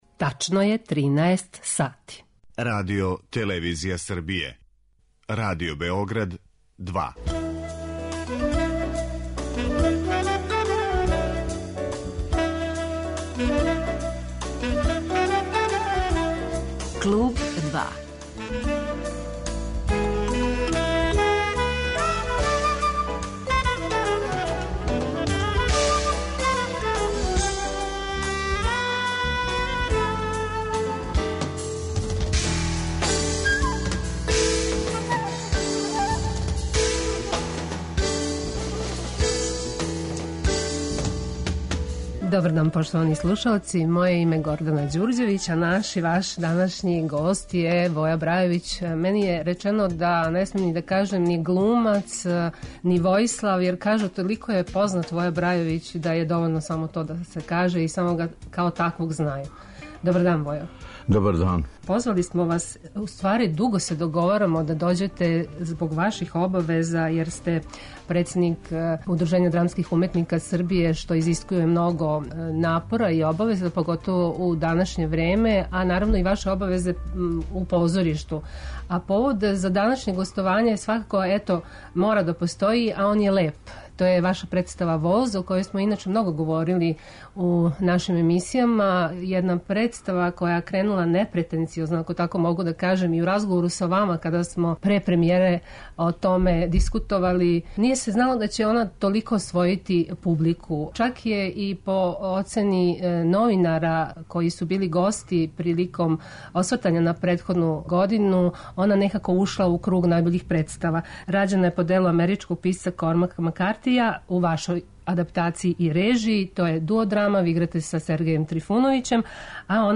Гост eмисије је Војислав Брајовић